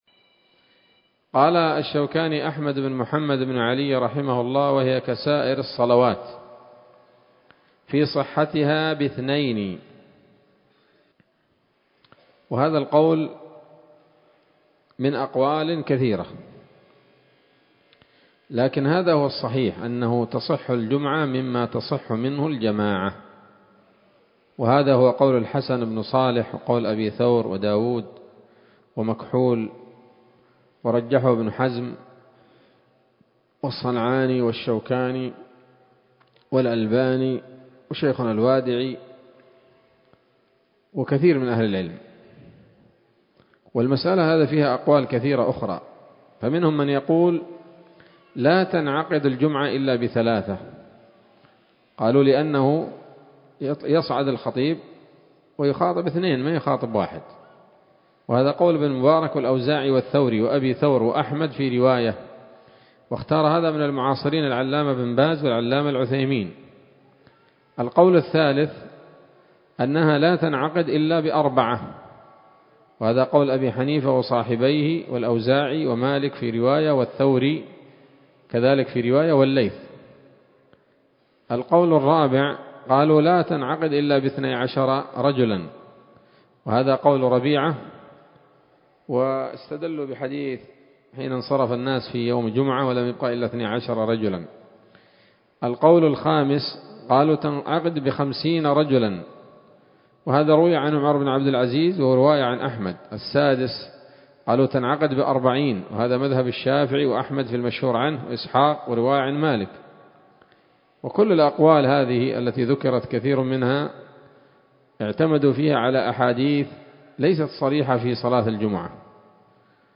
الدرس الحادي والأربعون من كتاب الصلاة من السموط الذهبية الحاوية للدرر البهية